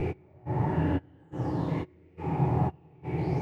Index of /musicradar/sidechained-samples/140bpm